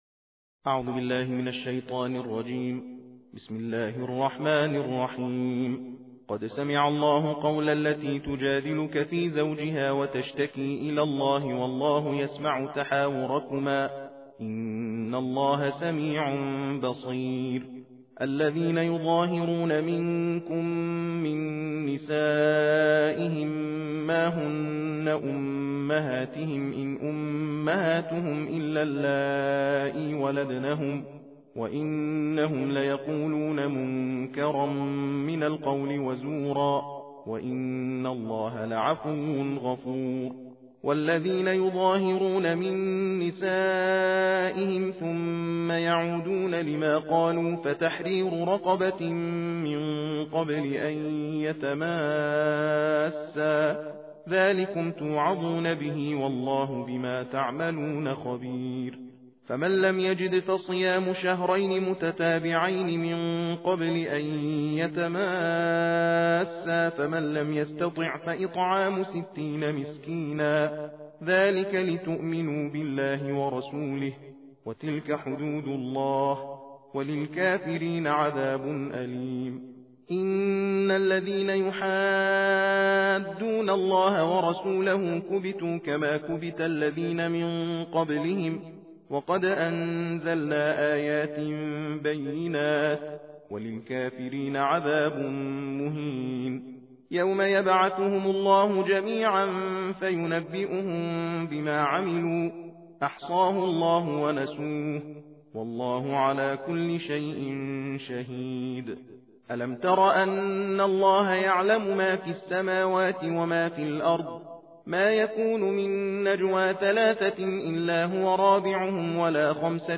تلاوت جزء بیست و هشتم قرآن کریم